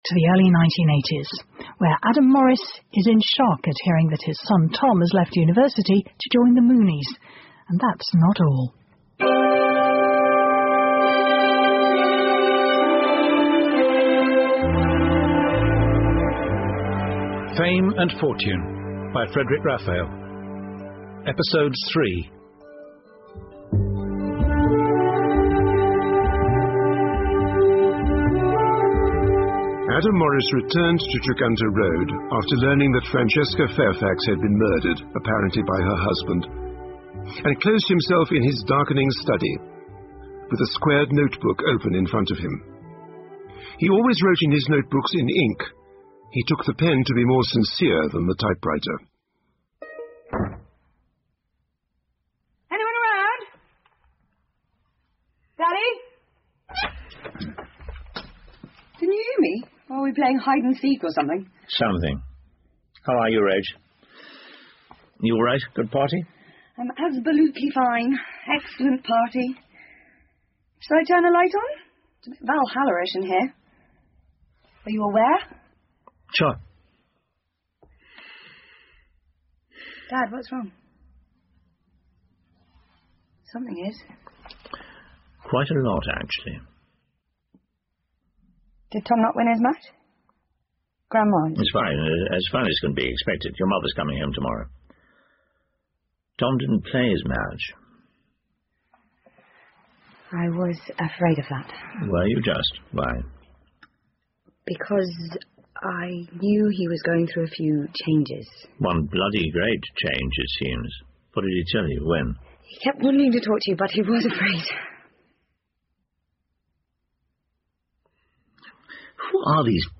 英文广播剧在线听 Fame and Fortune - 23 听力文件下载—在线英语听力室